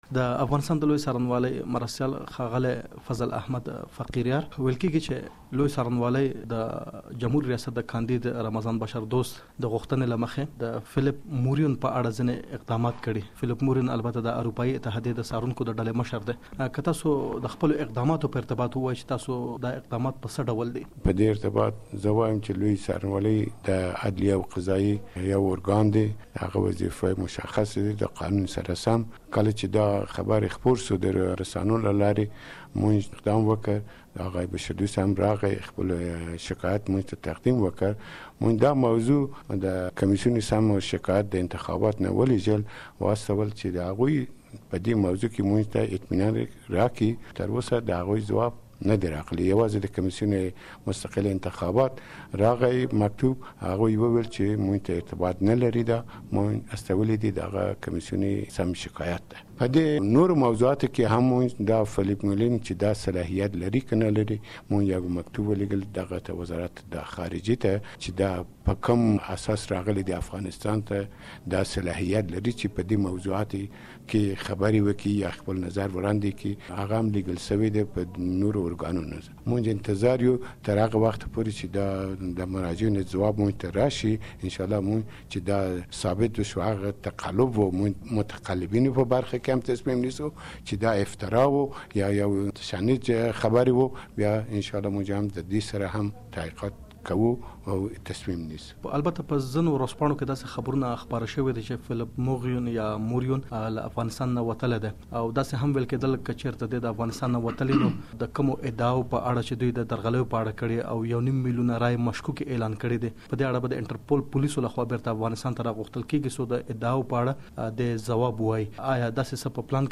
د لويې څارنوالۍ له مرستیال سره مرکه واورﺉ